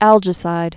(ăljĭ-sīd)